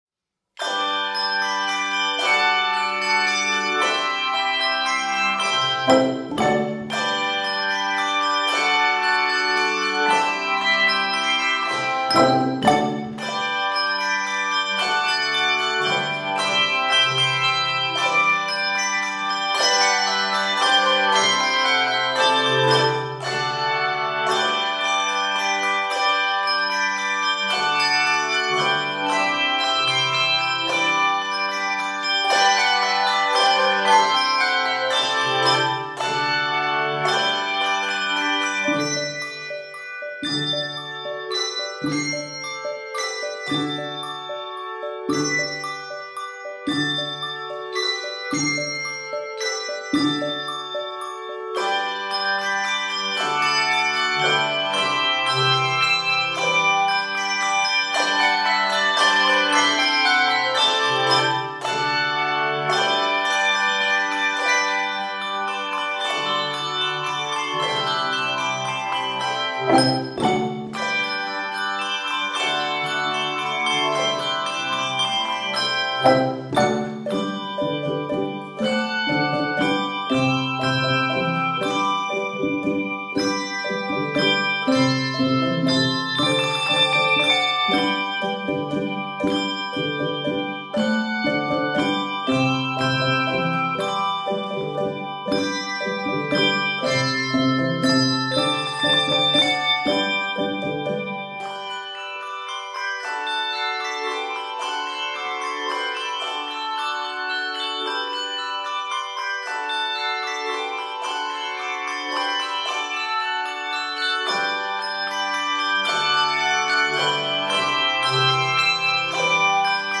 Here’s a jaunty setting of the hymn tune